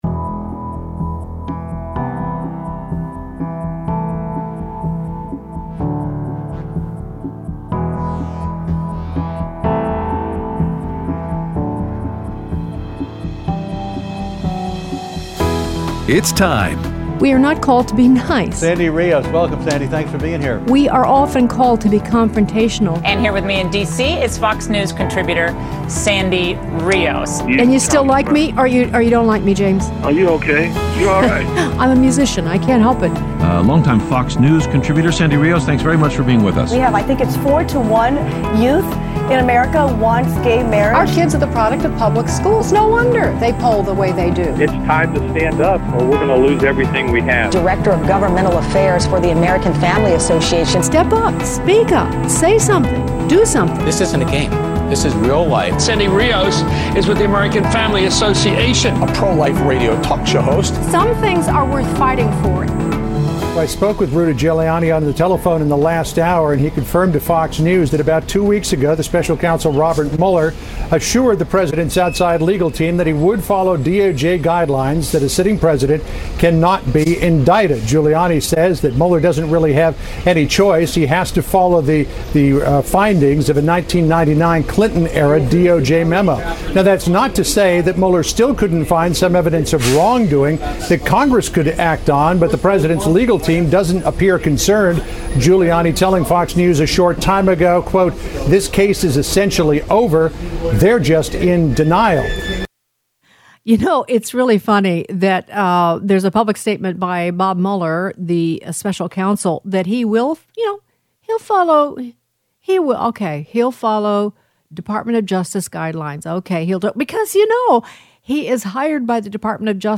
An Emotional Interview